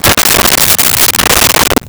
Dresser Drawer Opened 01
Dresser Drawer Opened 01.wav